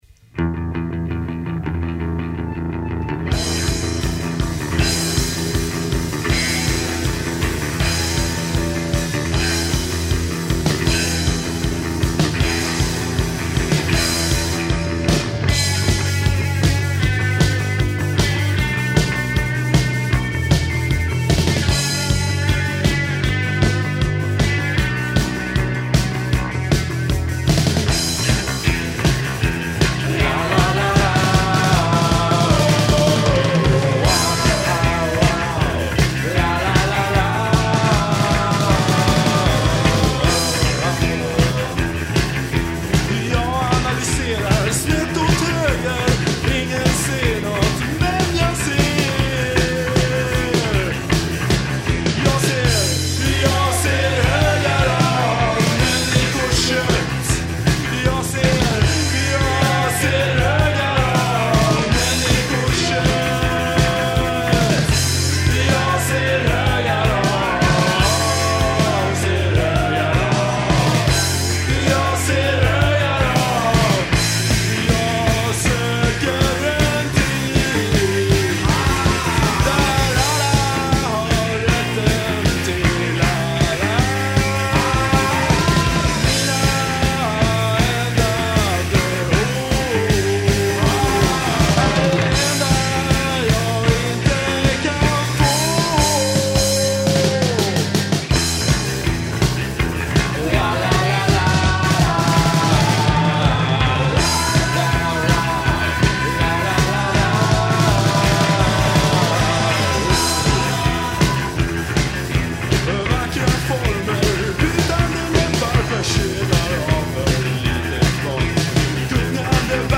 Bass, b-Stimme
Gitarre, b-Stimme
Trommel